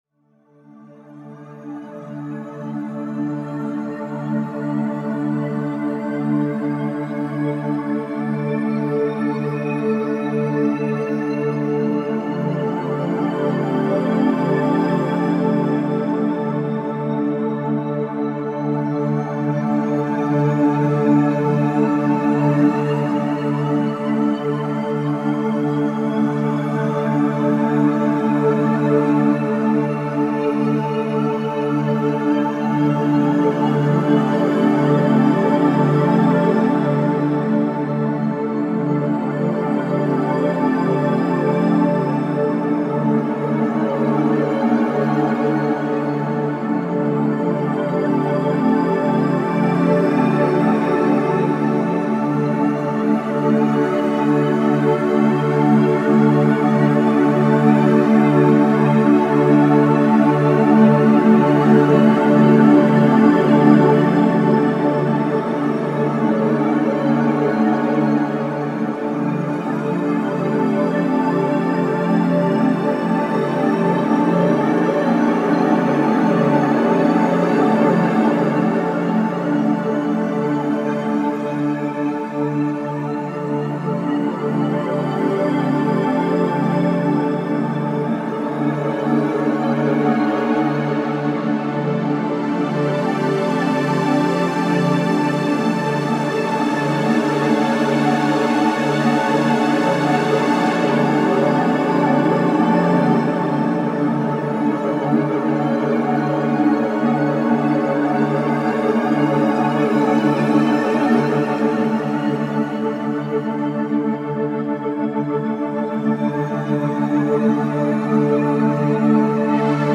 pads
- Long Spheric Ambient Pads -